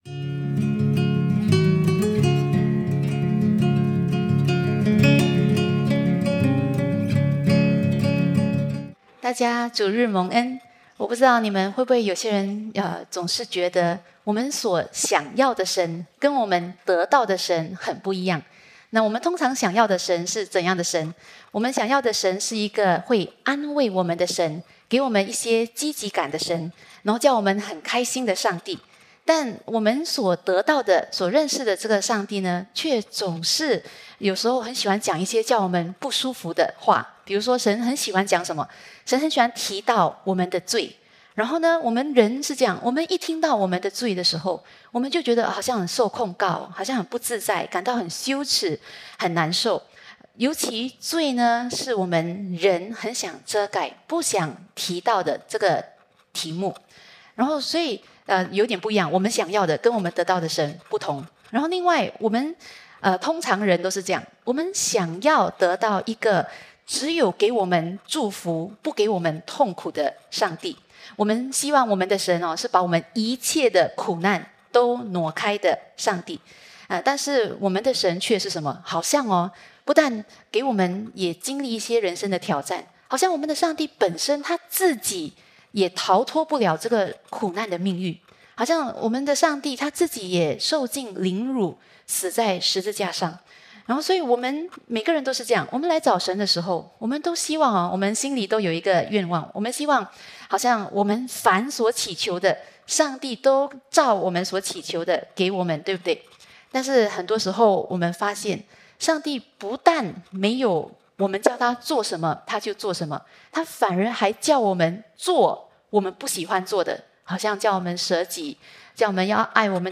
主日信息